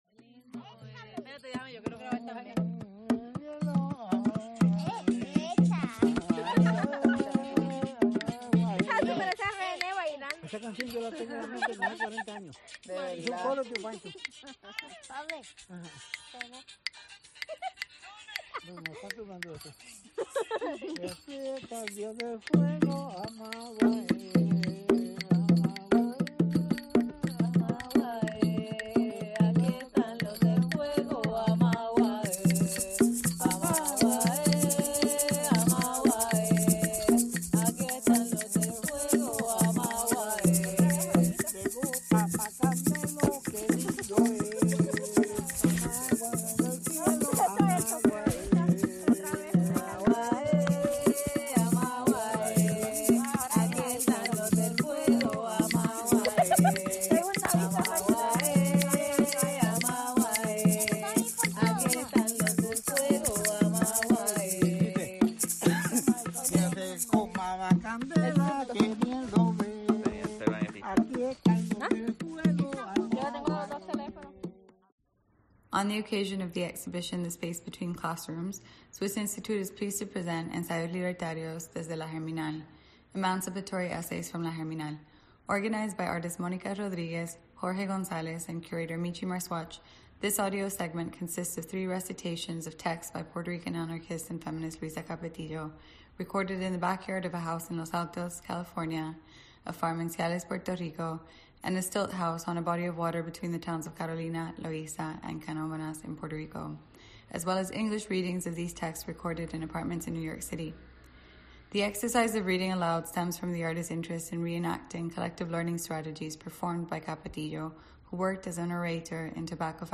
Recitation & Listening | Ensayos Libertarios: desde La Germinal / Emancipatory Essays: from La Germinal | Swiss Institute